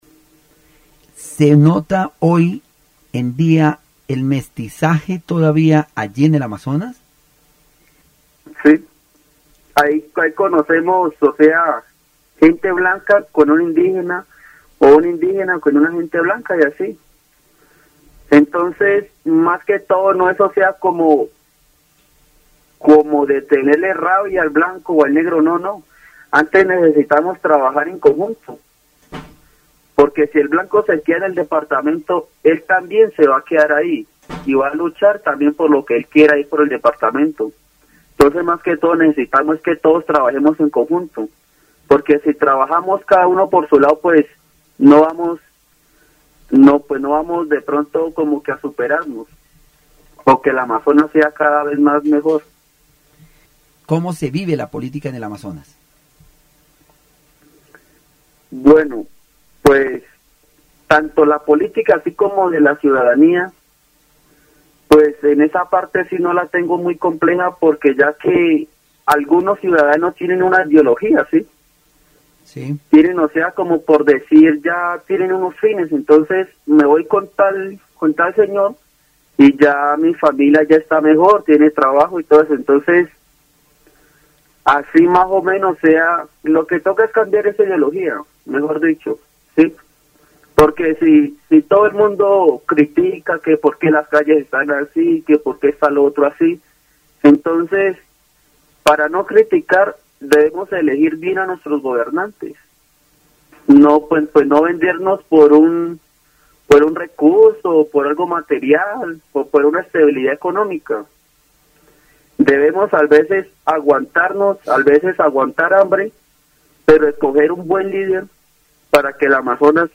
El programa radial presenta una conversación sobre el desarrollo de la juventud en el Amazonas, la importancia de la preservación cultural de los pueblos indígenas, el mestizaje en la región y la política local. Se destaca el papel del deporte como un medio de motivación y superación para los jóvenes, así como la necesidad de mejorar la educación en la región. Se menciona el esfuerzo de los curacas y líderes indígenas por rescatar las lenguas nativas y transmitirlas a las nuevas generaciones.